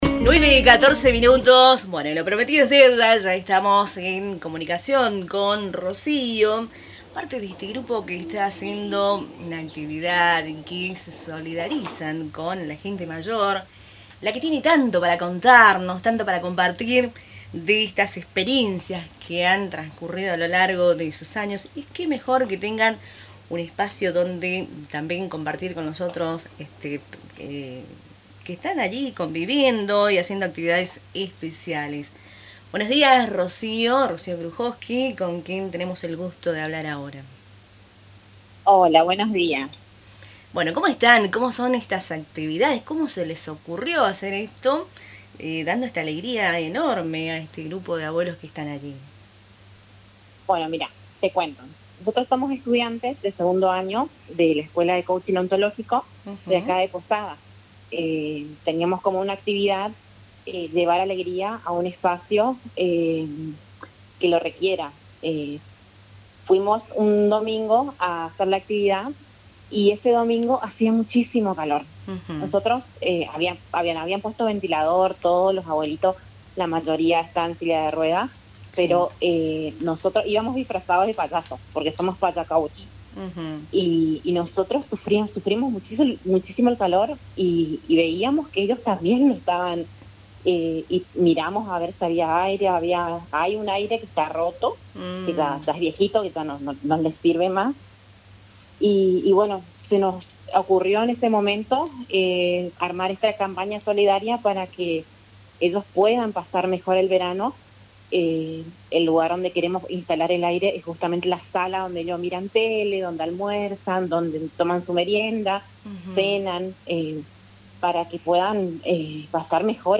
compartió en diálogo con Radio Tupa Mbae cómo nació la iniciativa